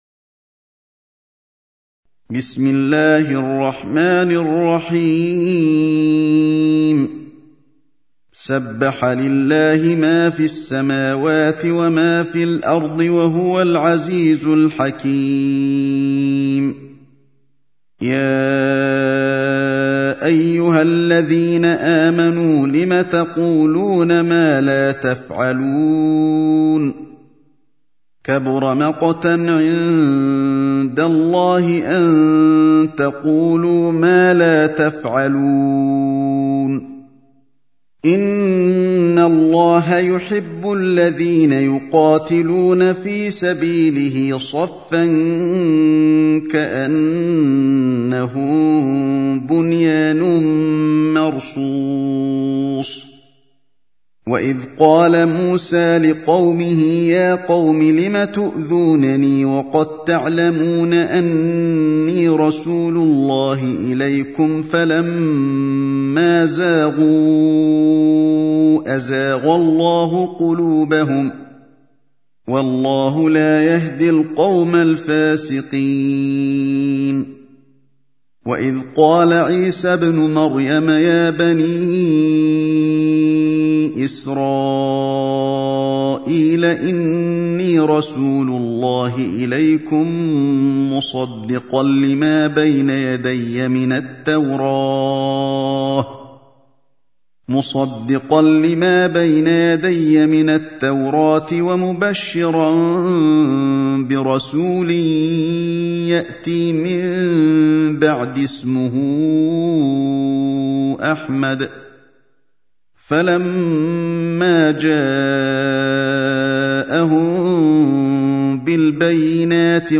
سورة الصف | القارئ علي الحذيفي